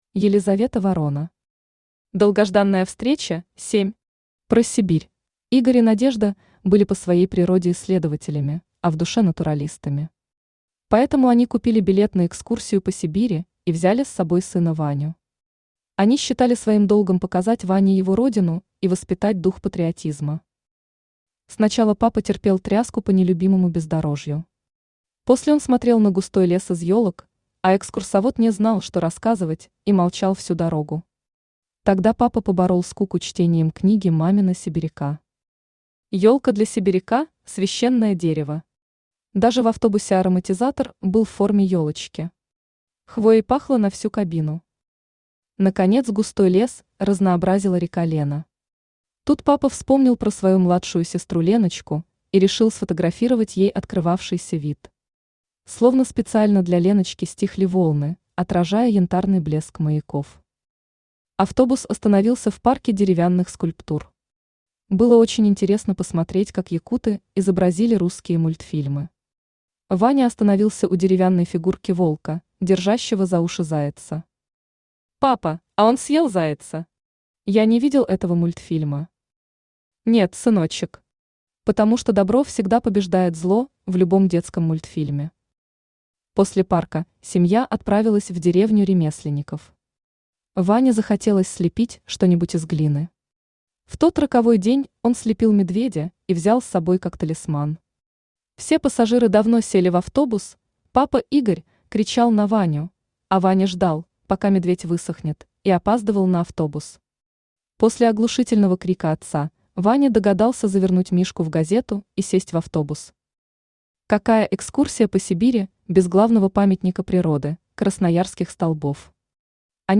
Аудиокнига Долгожданная встреча 7. Про Сибирь | Библиотека аудиокниг
Про Сибирь Автор Елизавета Сергеевна Ворона Читает аудиокнигу Авточтец ЛитРес.